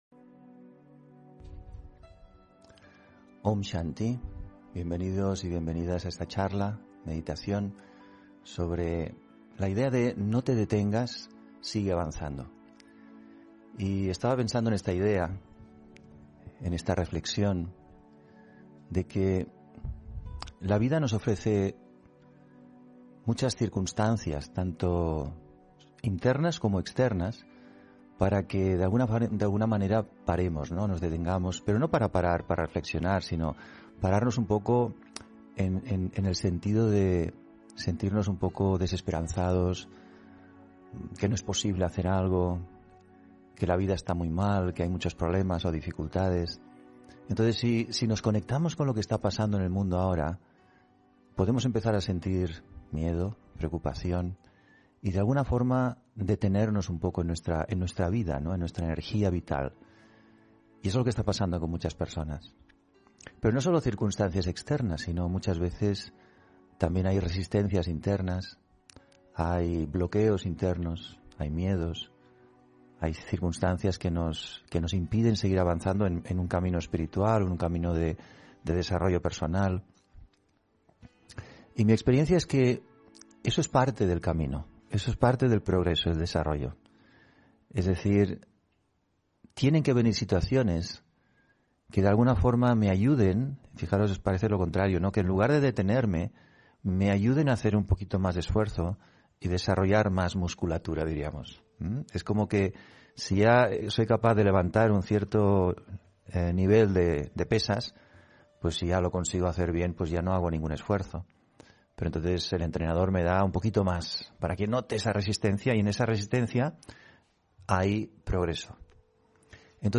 Meditación y conferencia: Sigue avanzando, no te detengas (12 Noviembre 2021)